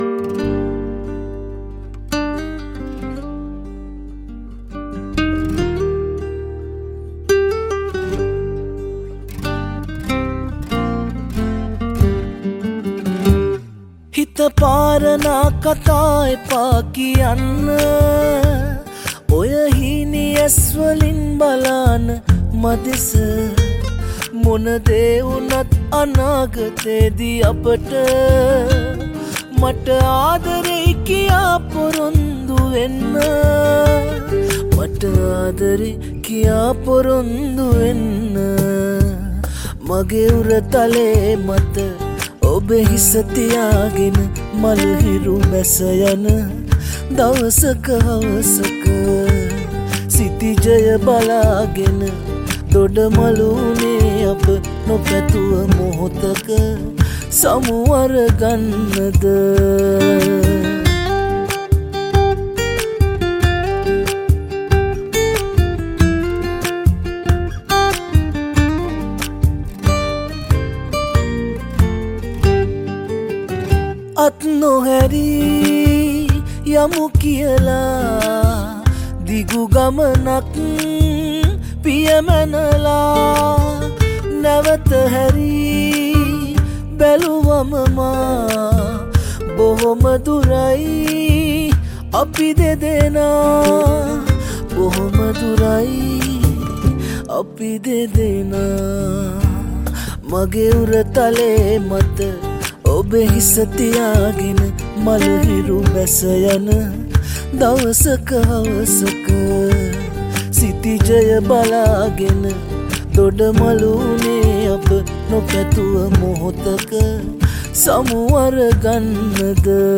sinhala pop songs